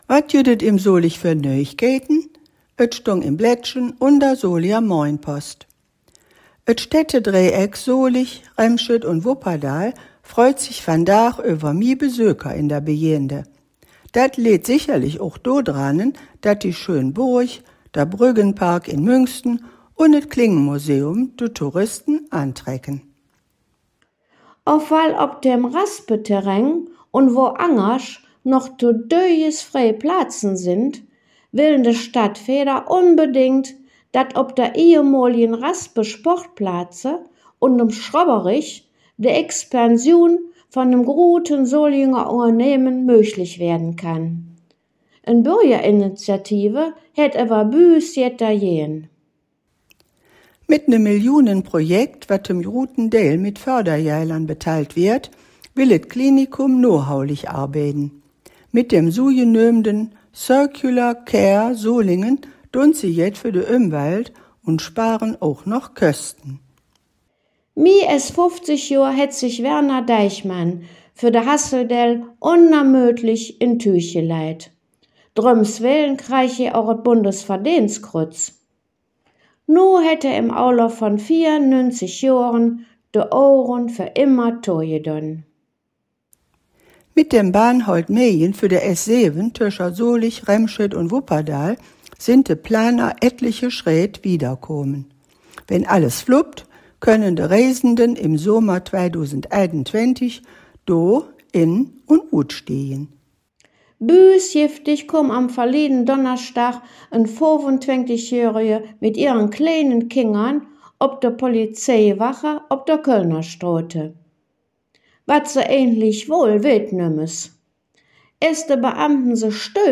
Folge 270 der Nachrichten in Solinger Platt von den Hangkgeschmedden. U.a.: Besüöker op Schlot Burg, Isbahn Itterdall, Fasselowend en Ohligs